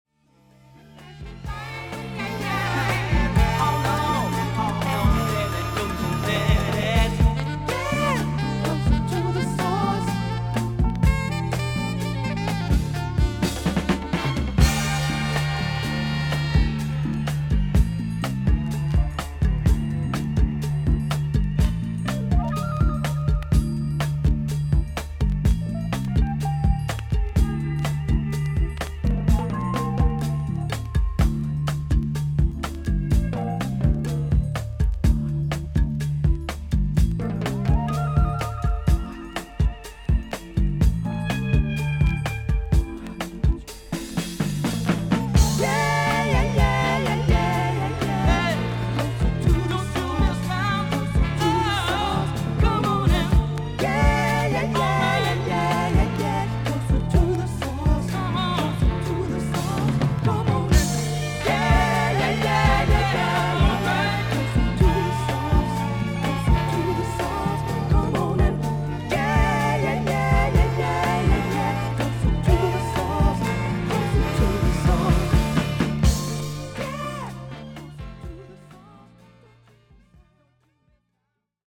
B1後半に3mmほどのキズ、少々軽い周回ノイズあり。
ほかはVG+〜VG++:少々軽いパチノイズの箇所あり。少々サーフィス・ノイズあり。クリアな音です。
ソウル・シンガー/ソング・ライター/ギタリスト。